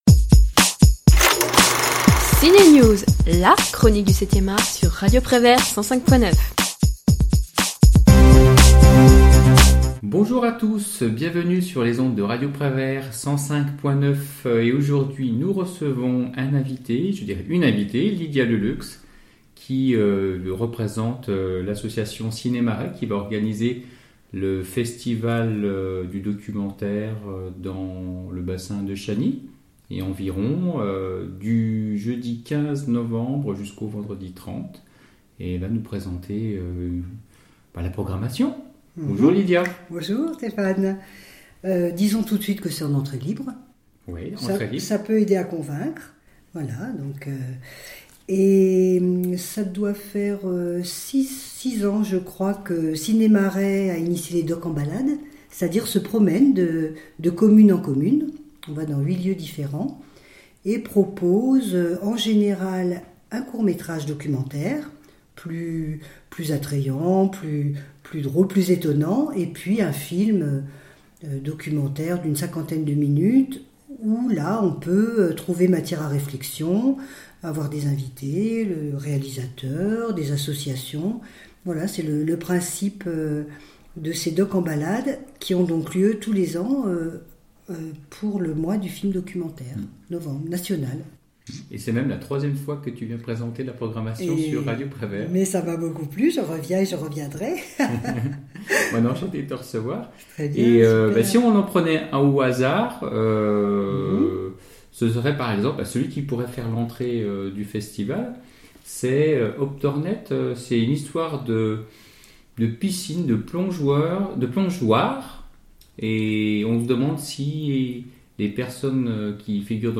L'Interview de Radio Prévert / Émissions occasionnelles Podcasts